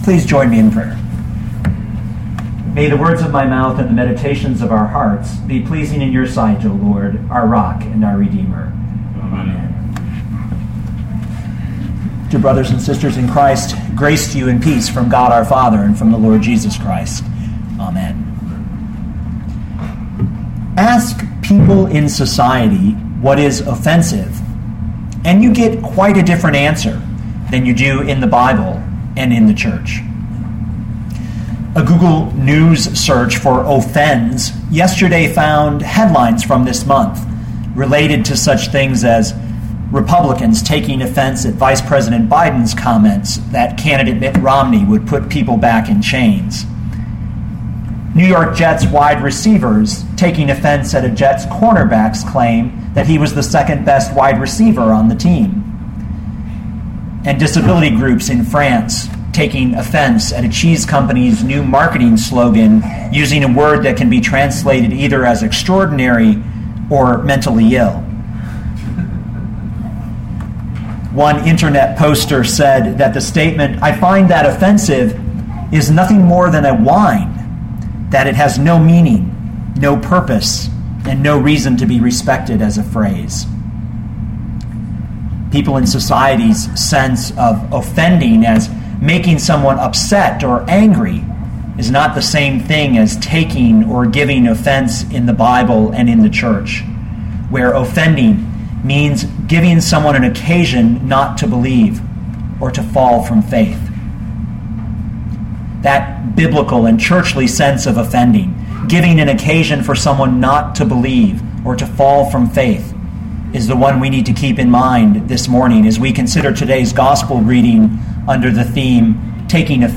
2012 John 6:51-69 Listen to the sermon with the player below, or, download the audio.